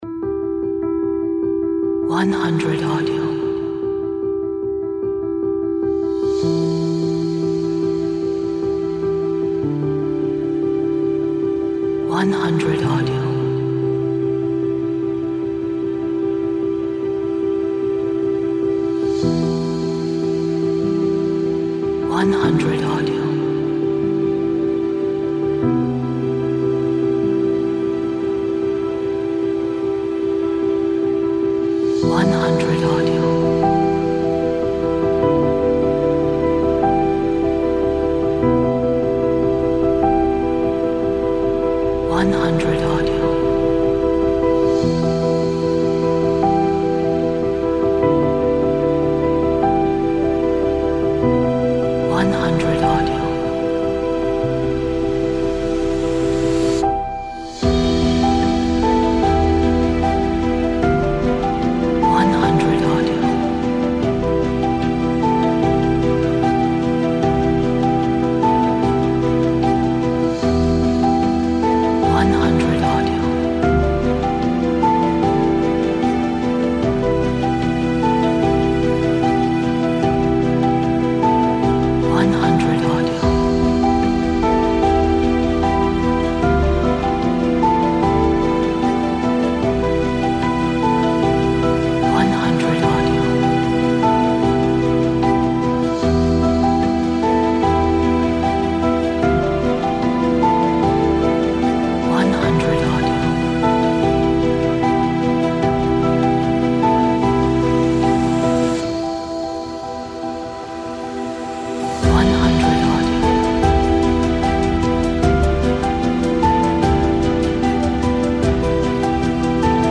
Soft Cinematic Background